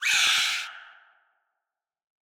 PixelPerfectionCE/assets/minecraft/sounds/mob/guardian/land_hit4.ogg at mc116
land_hit4.ogg